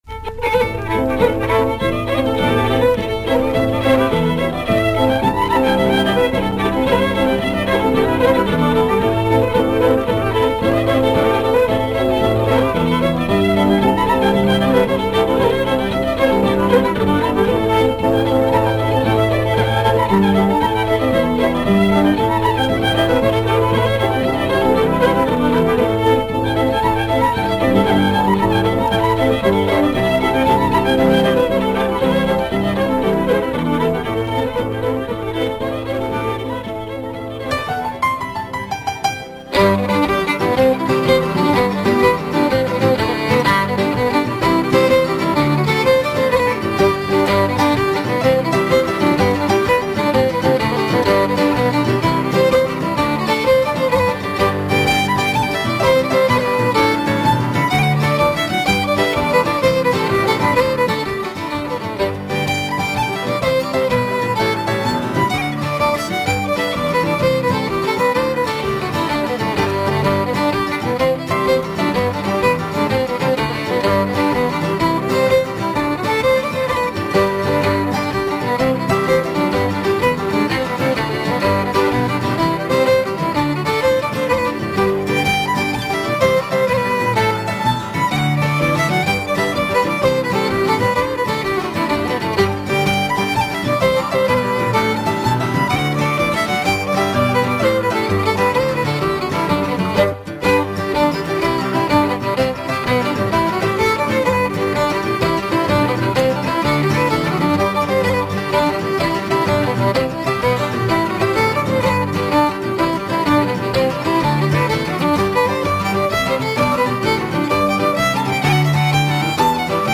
Of course, they were all played and sung by folks from Atlantic Canada.